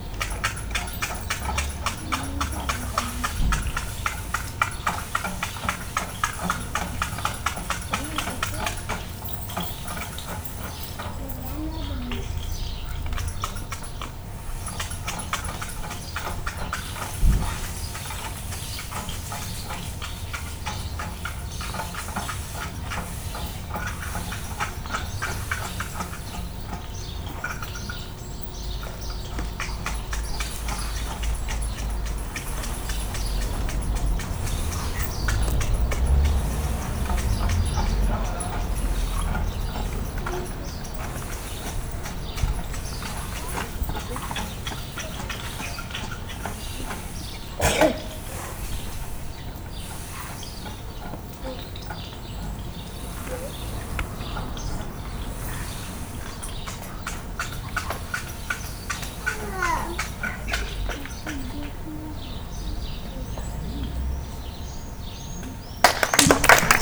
-les bâtons de pluie
-les crécelles
-les élastophones
-les xylophone
-les maracas
Ensuite nous avons expérimenté, manipulé, produits des sons en essayant chaque instrument.
Pour finir, chaque groupe a joué un petit concert avec les instruments.